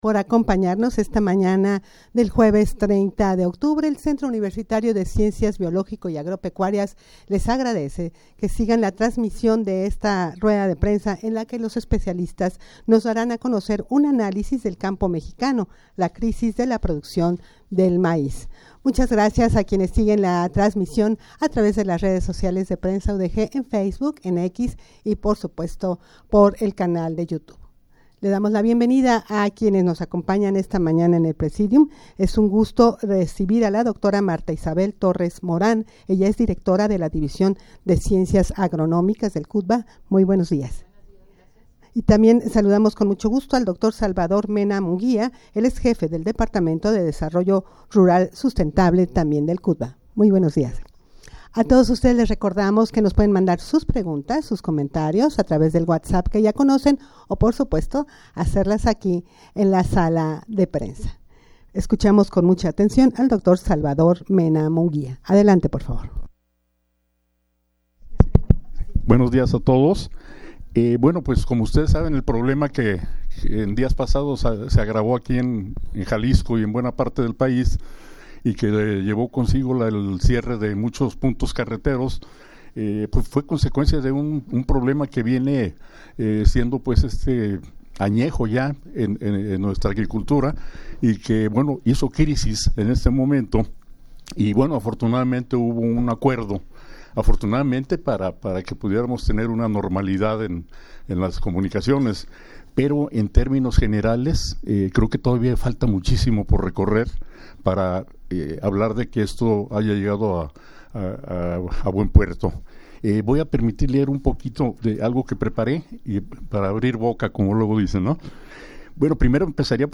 rueda-de-prensa-para-dar-a-conocer-analisis-del-campo-mexicano-la-crisis-de-la-produccion-de-maiz.mp3